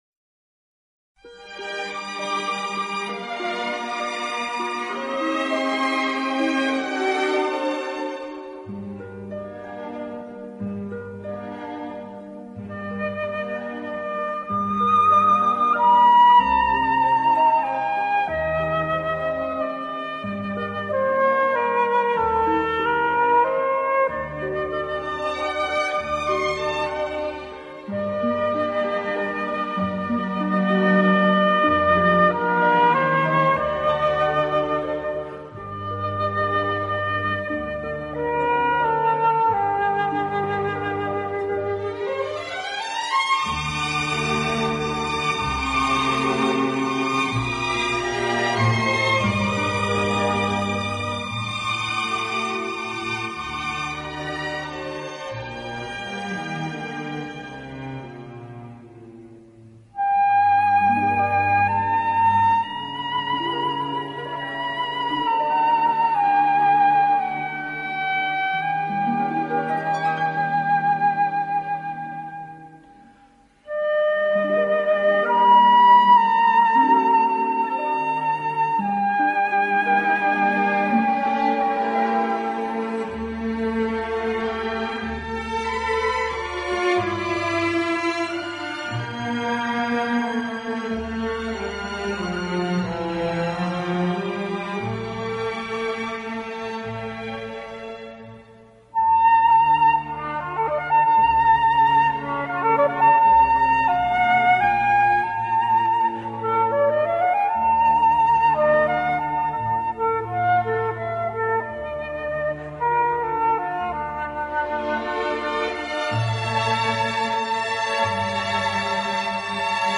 有的音乐改编自古典音乐经典片段、好莱坞浪漫巨片和流行金榜名曲。